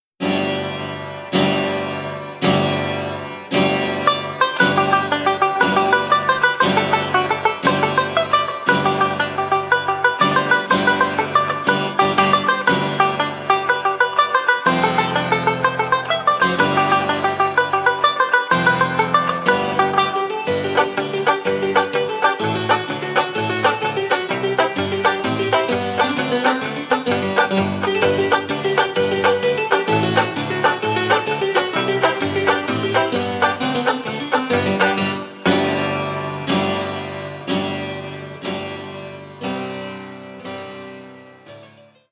Traditional Irish Jig
4-beat intro.
This song is in 6/8 time.